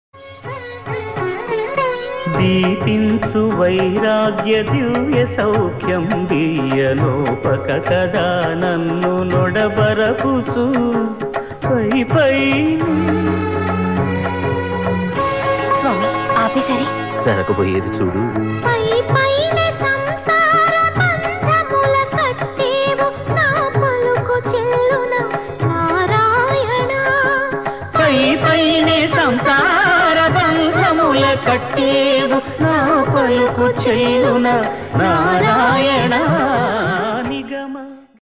Telugu Bhakti ringtone | best flute ringtone download
hindu caller tone download